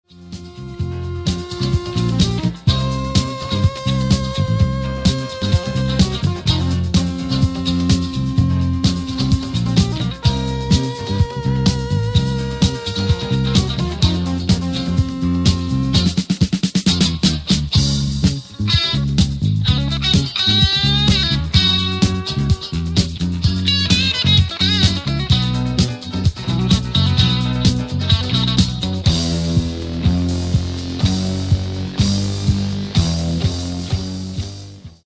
Genere: Funk | Soul